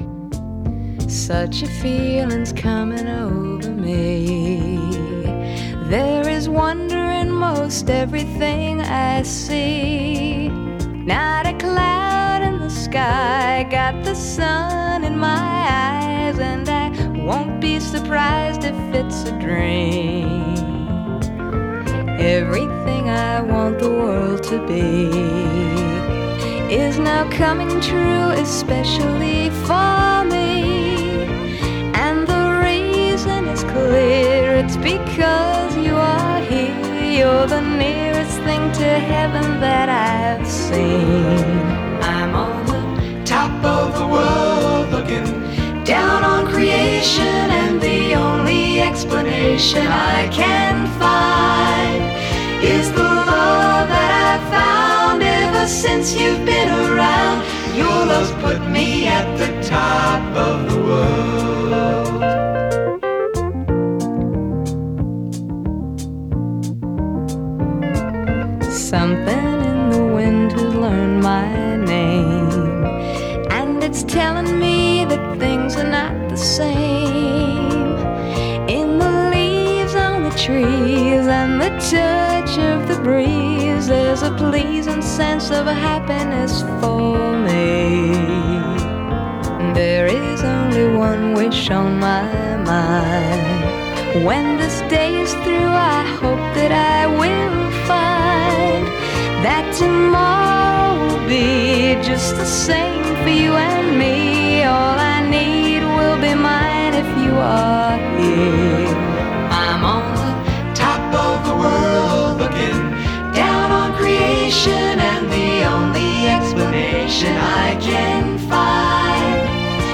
(captured from webcast)
album version